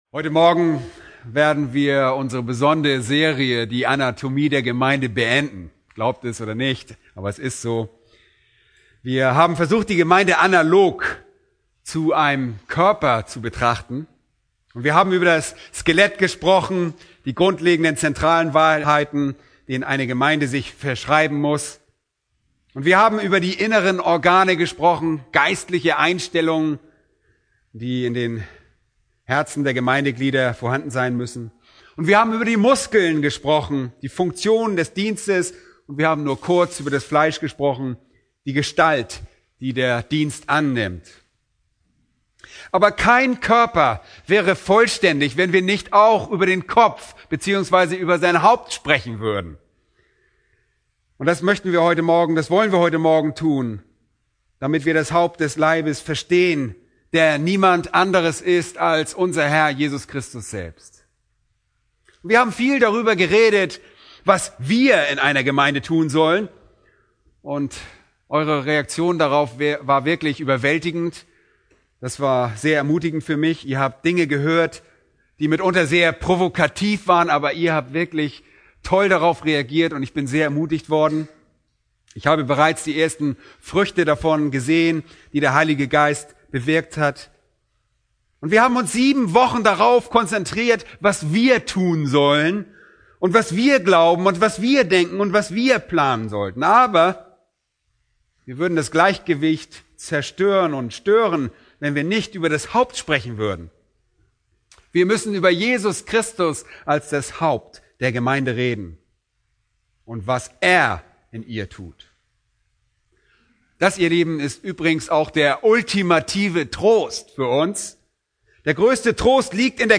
Predigten Übersicht nach Serien Startseite Predigt-Archiv Predigten Übersicht nach Serien